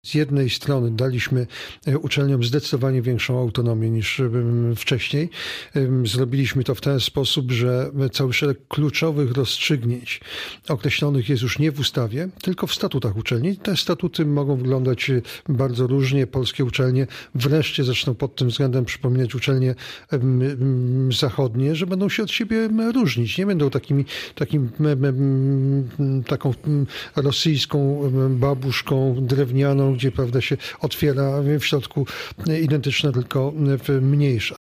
Wicepremier oraz minister nauki i szkolnictwa wyższego przypomniał w Radiu Zachód, że konstytucja weszła w życie rok temu.
poranny-gosc-gowin-1.mp3